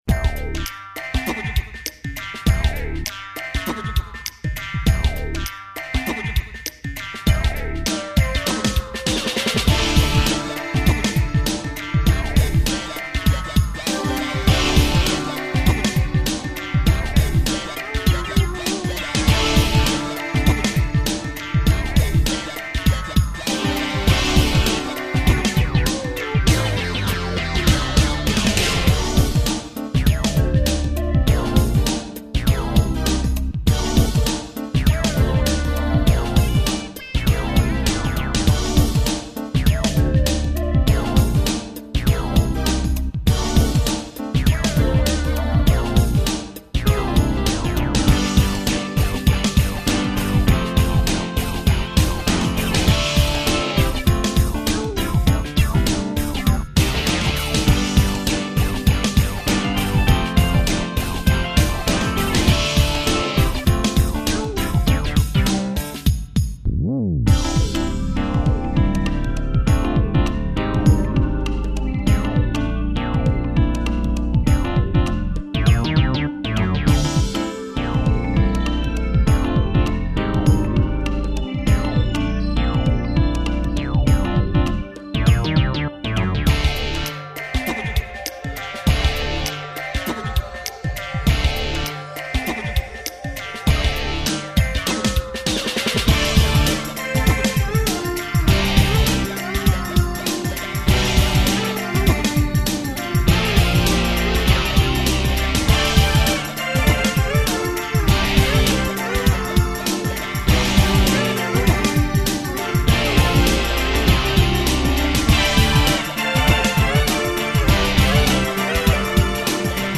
hear internal sequence
Synthesis: PCM rompler